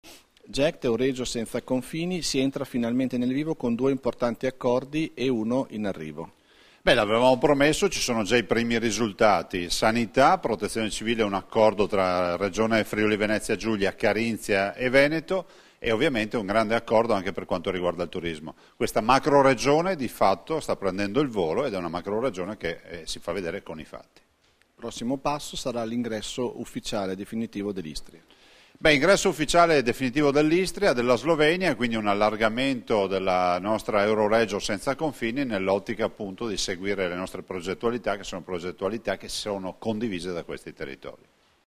Dichiarazioni di Luca Zaia (Formato MP3) [667KB]
a margine dell'Assemblea del Gruppo Europeo di Cooperazione Territoriale (GECT) "Euregio Senza Confini", rilasciate a Trieste il 22 dicembre 2014